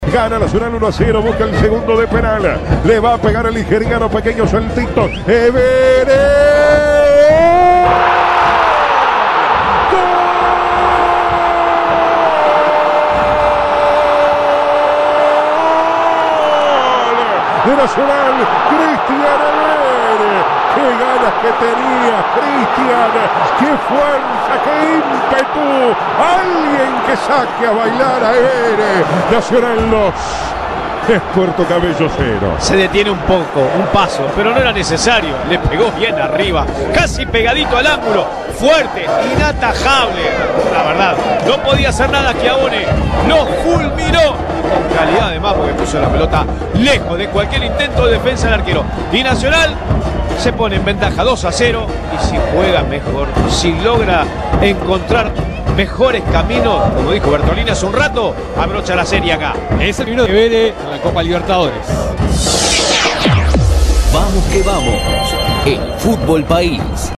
El debut tricolor en copa en la voz del equipo de Vamos que Vamos